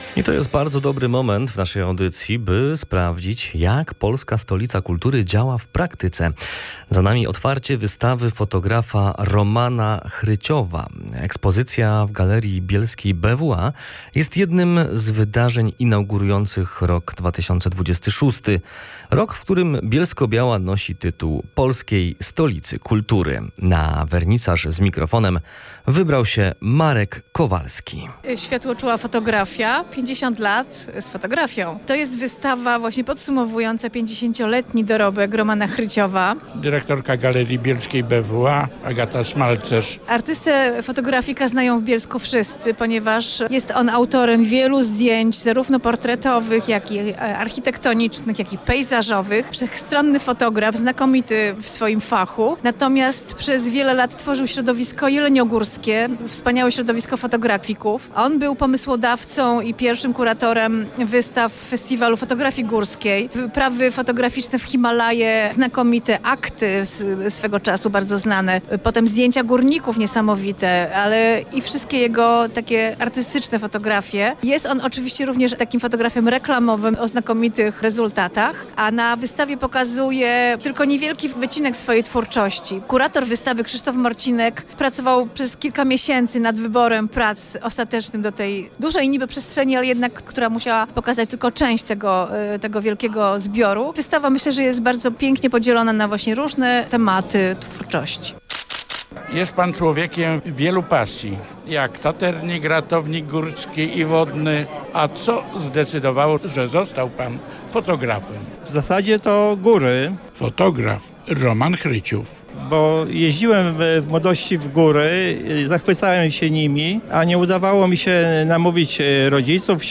Polskie Radio Katowice - informacja o wystawie